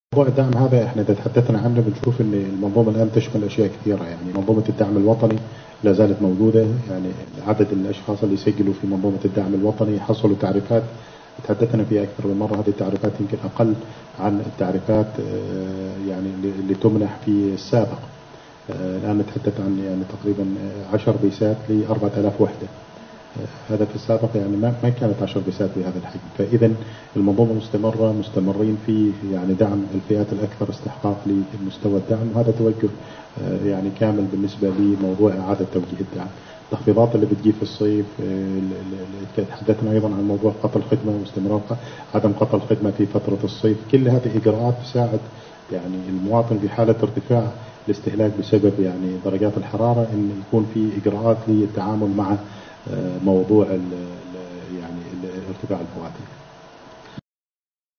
وقال خلال اللقاء